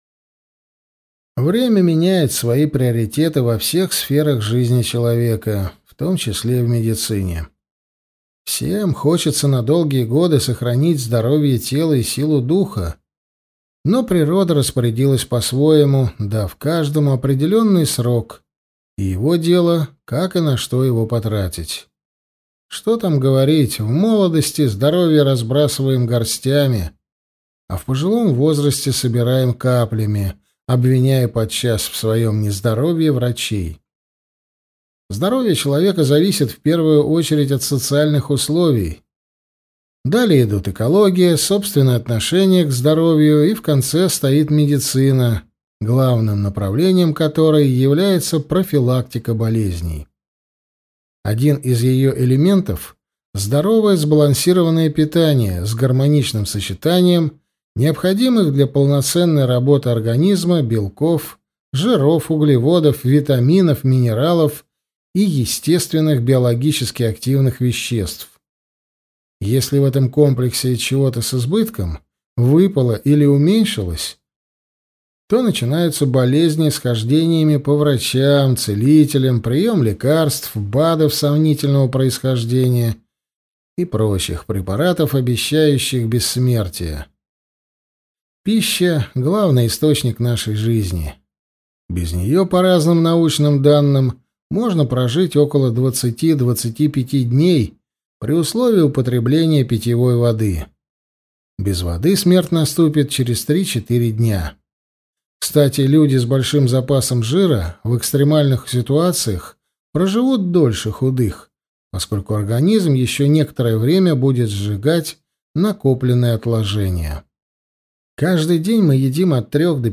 Аудиокнига Здоровое питание лечит | Библиотека аудиокниг